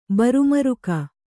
♪ baru maruka